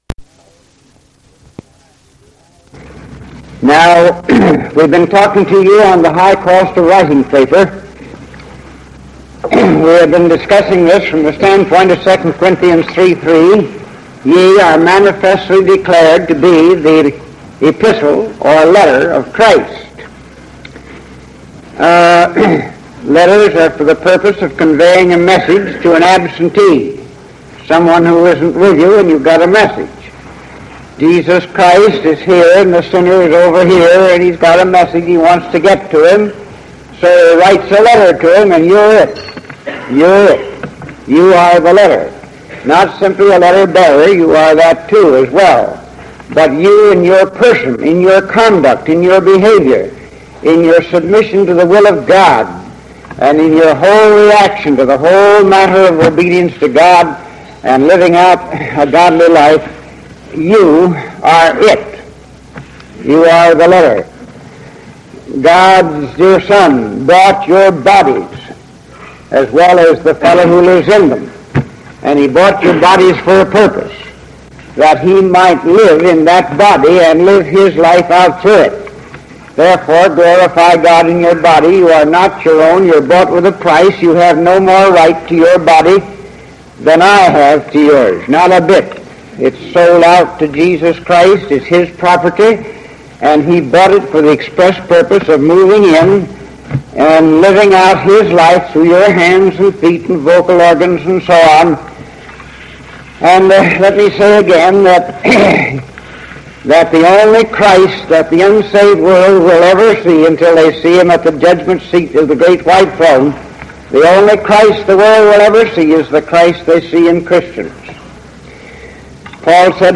In this sermon, the speaker discusses the process that a tree goes through before it can be transformed into writing paper. He compares this process to the transformation that believers in Christ must undergo.